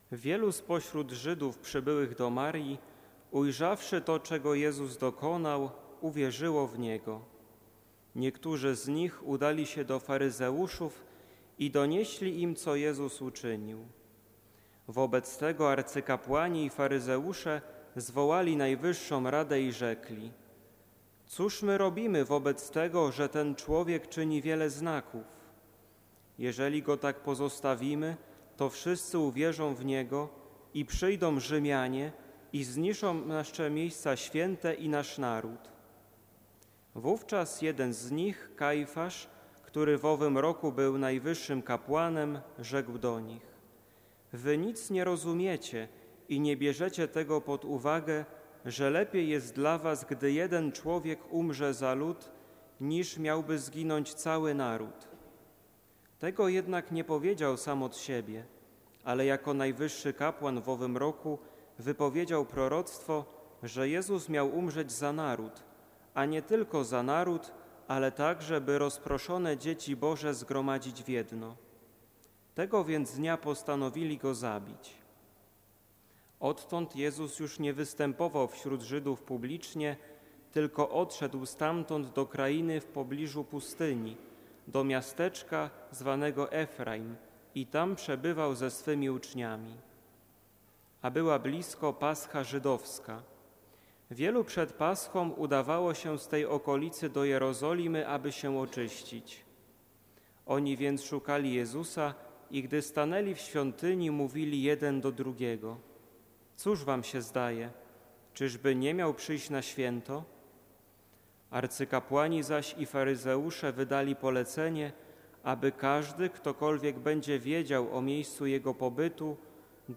31 marca br. przypada 40. rocznica święceń kapłańskich naszego arcybiskupa Józefa Kupnego, z tej okazji zapraszamy duchowieństwo i wiernych naszej Archidiecezji do katedry wrocławskiej na uroczystą Eucharystię w sobotę 1 kwietnia o godzinie 11.00, której przewodniczyć będzie dostojny Jubilat.
homilia-1.04-katedra.mp3